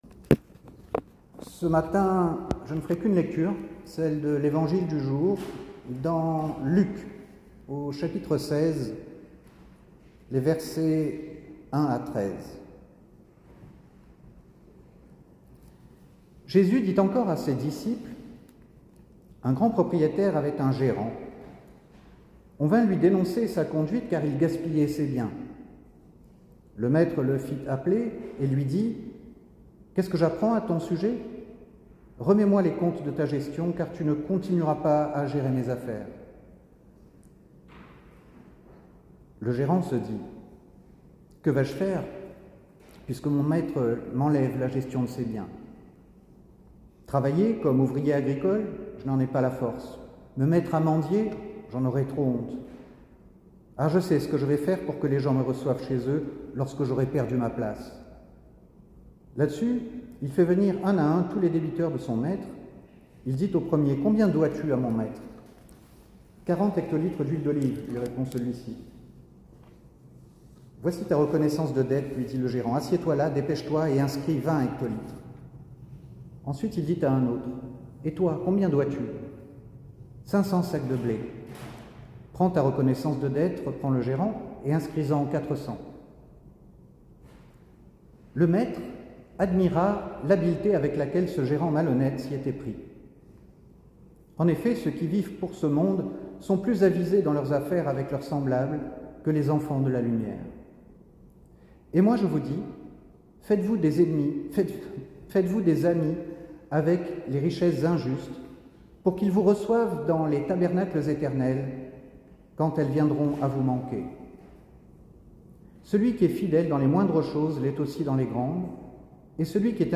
Culte du dimanche 18 septembre 2022
Enregistrement audio de la prédication